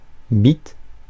Ääntäminen
Ääntäminen France: IPA: [bit] France: IPA: [ɛ̃ bit] Haettu sana löytyi näillä lähdekielillä: ranska Käännöksiä ei löytynyt valitulle kohdekielelle.